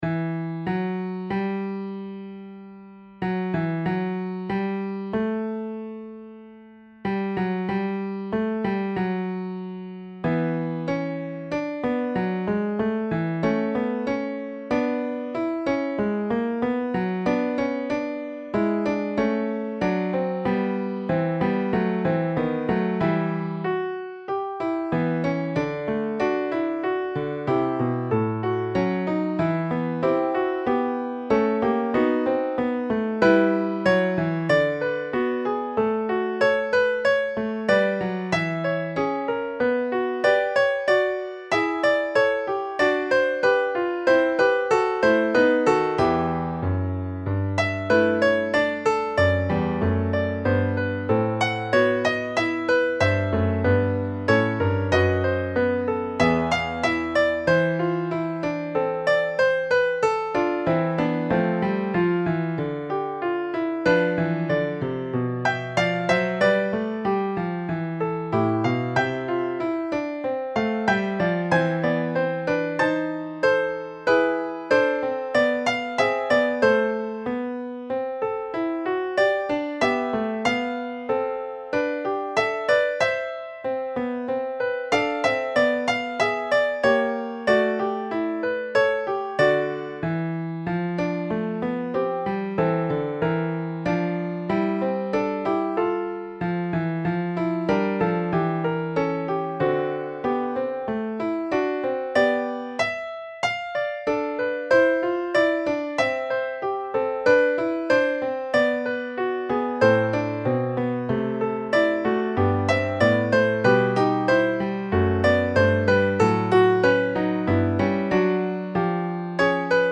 Fugue, D952
schubertfugue.mp3